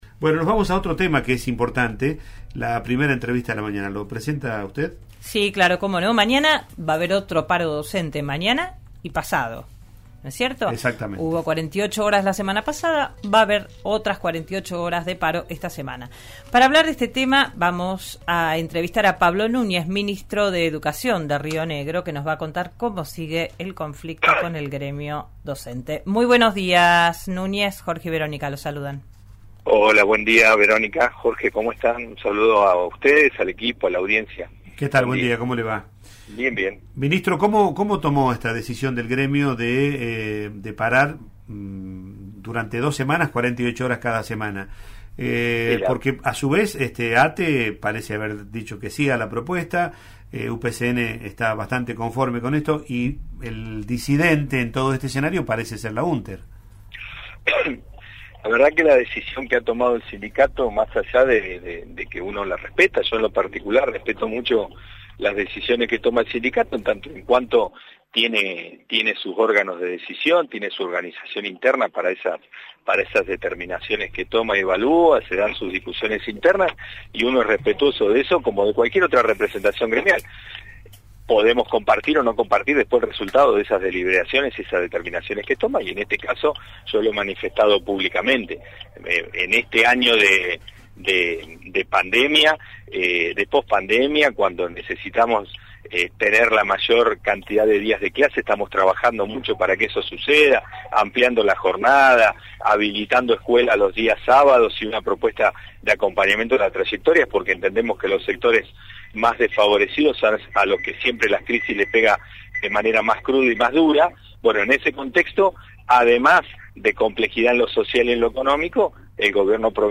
Pablo Núñez, ministro de Educación de Río Negro, habló esta mañana con RN Radio. En una entrevista brindada a Digan lo que Digan se refirió al actual paro docente llevado adelante por la Unter.